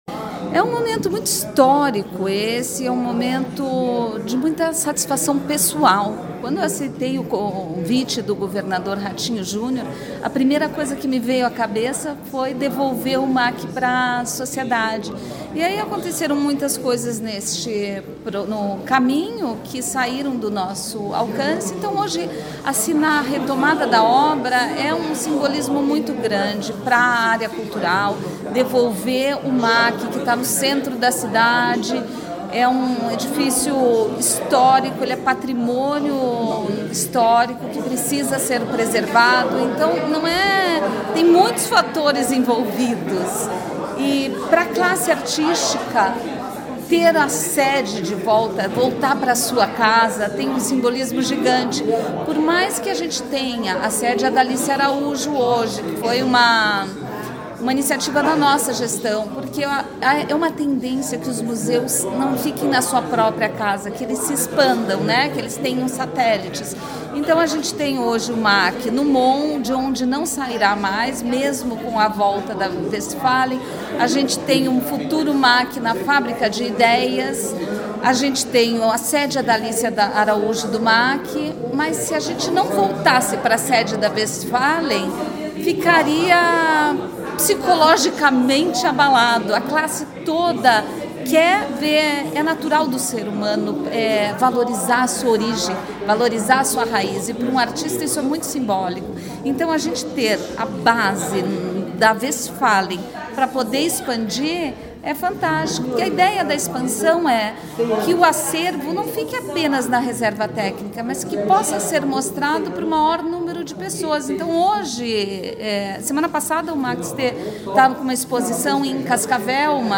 Sonora da secretária da Cultura, Luciana Casagrande Pereira, sobre o acordo para retomada das obras do Museu de Arte Contemporânea